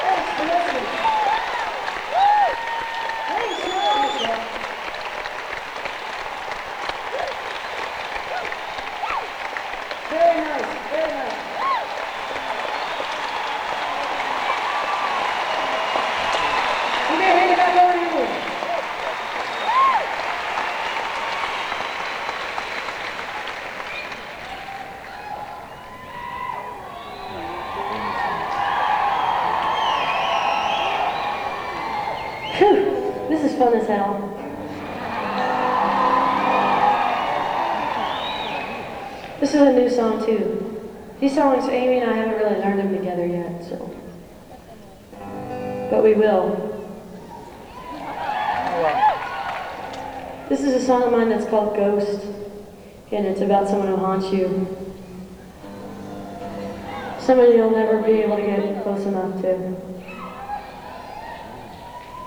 lifeblood: bootlegs: 1991-09-01: seattle center coliseum - seattle, washington (alternate recording - 24 bit version)
14. talking with the crowd (1:02)